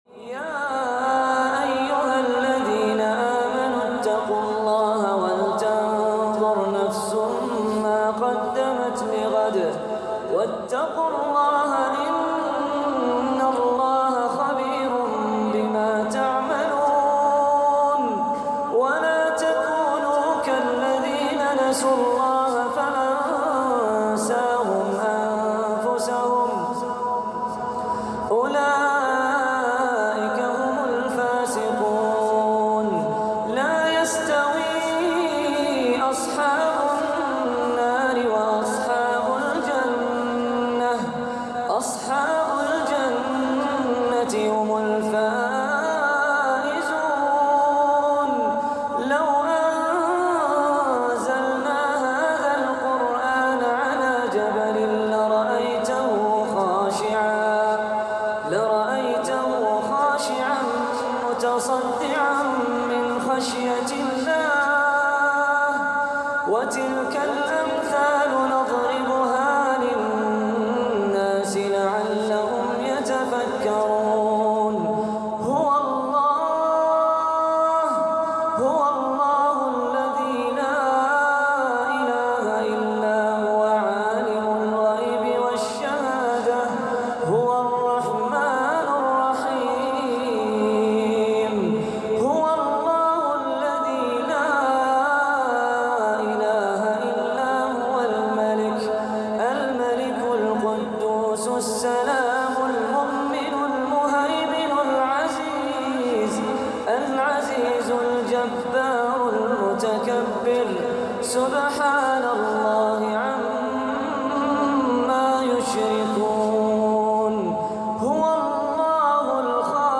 سورة الحشر الآيه 18-24 للقارئ